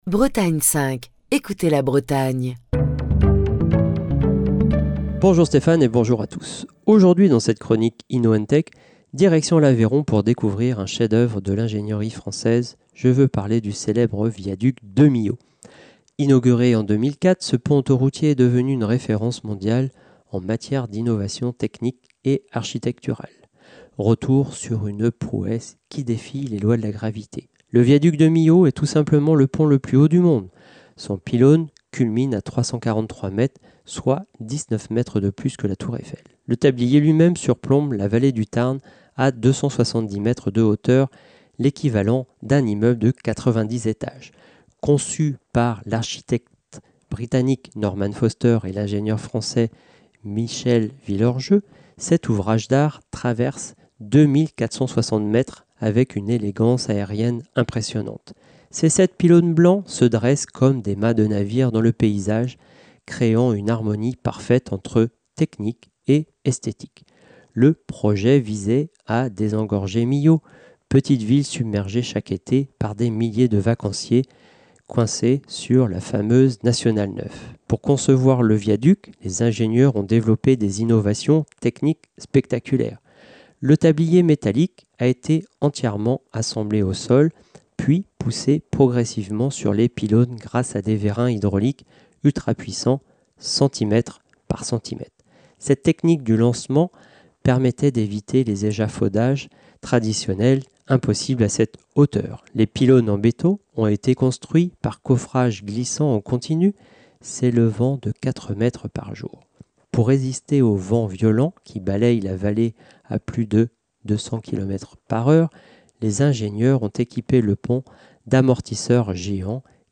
Chronique du 19 décembre 2025.